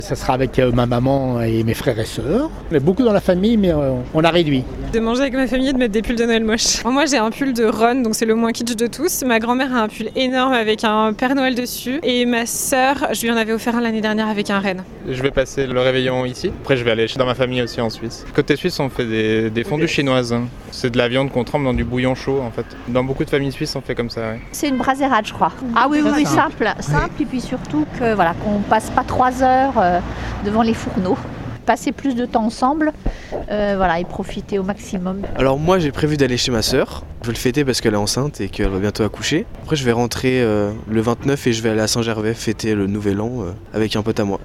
ODS Radio a interrogé les passants, en Haute-Savoie, pour savoir comment ils fêteront Noël cette année.